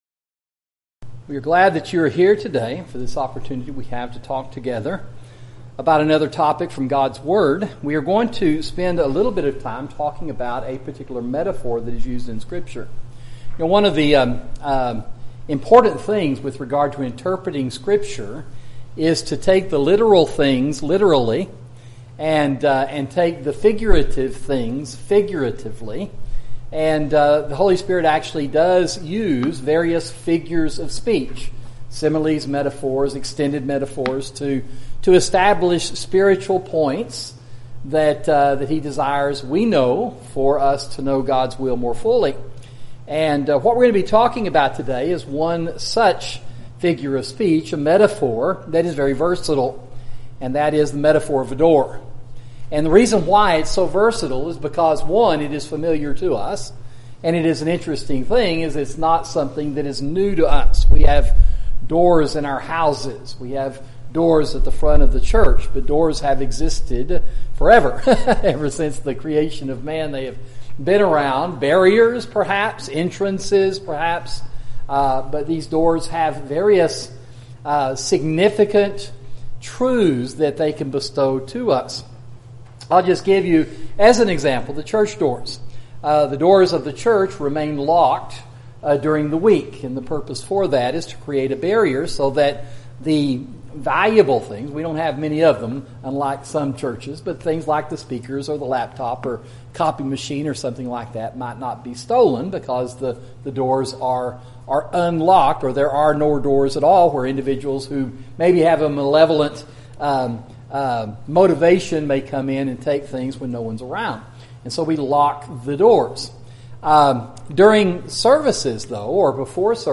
Sermon: A Metaphor – Doors – Sound Teaching